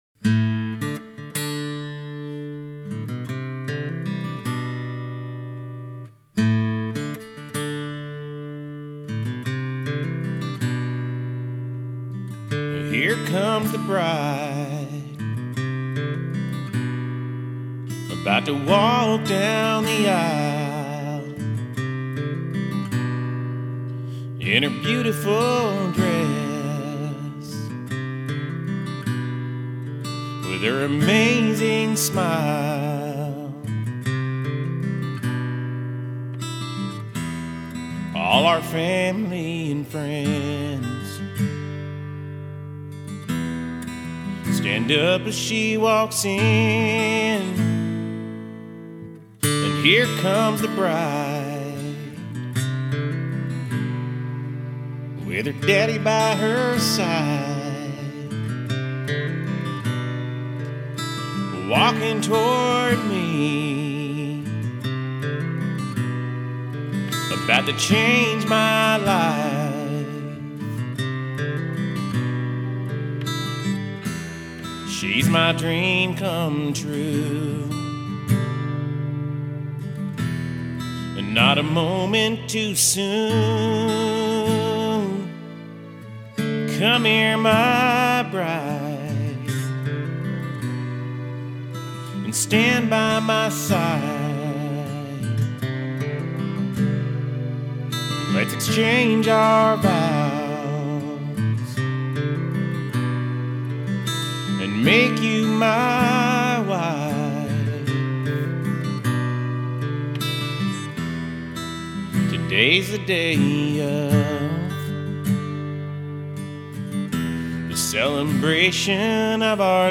for anyone looking for a wedding march with a country sound
We wanted a country style wedding march for our wedding.